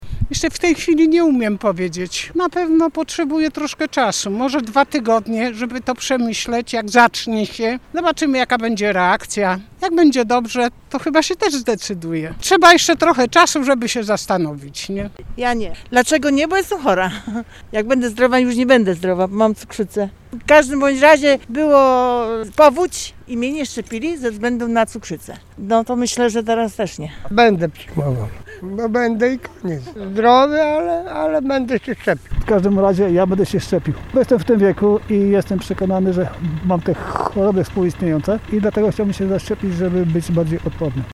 Zapytaliśmy dziś zielonogórzan, czy zdecydują się na przyjęcie szczepionki na koronawirusa: